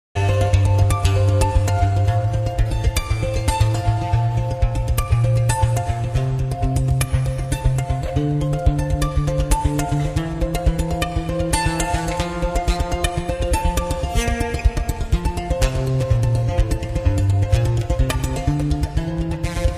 Yes, that's just one guitar.